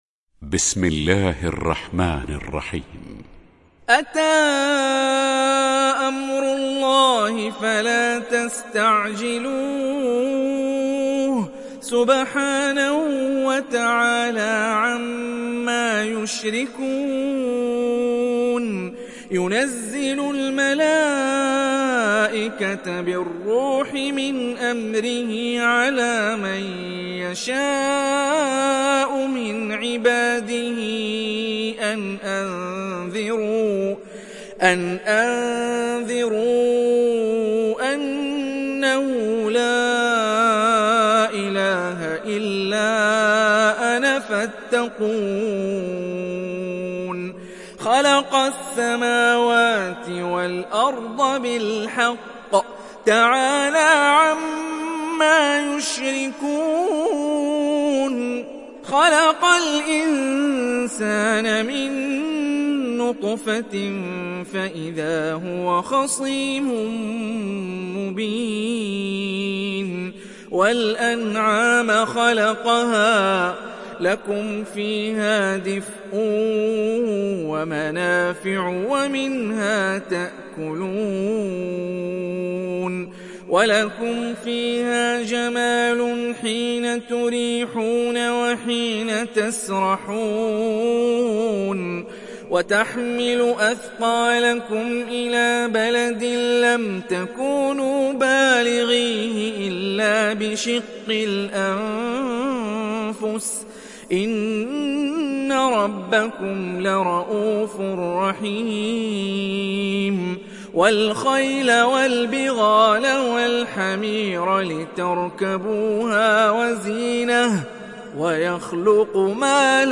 Surat An Nahl mp3 Download Hani Rifai (Riwayat Hafs)